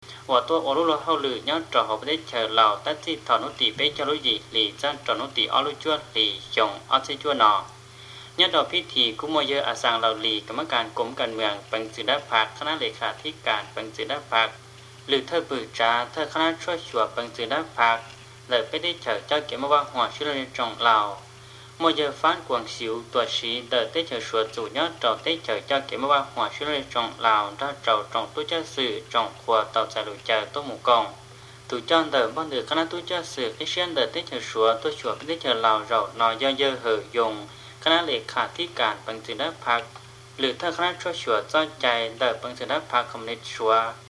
Here’s a recording of part of a news report in a mystery language.